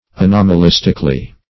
Anomalistically \A*nom`a*lis"tic*al*ly\, adv.
anomalistically.mp3